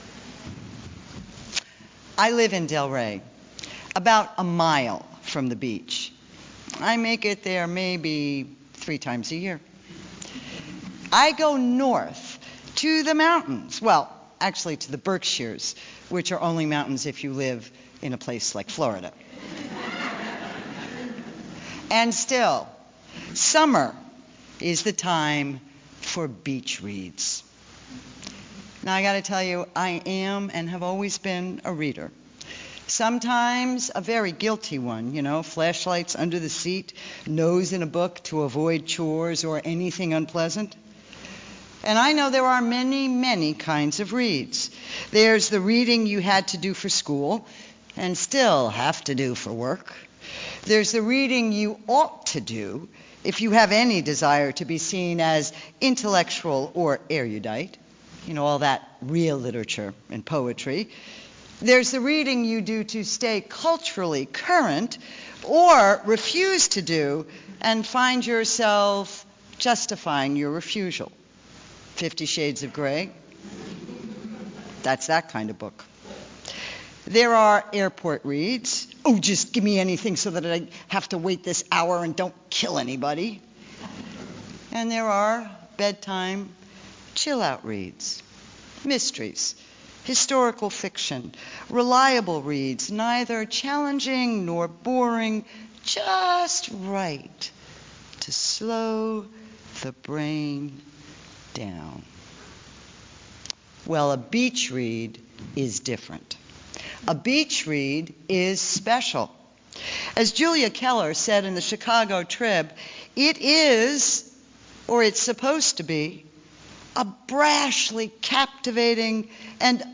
by | Sep 1, 2013 | Recorded Sermons | 0 comments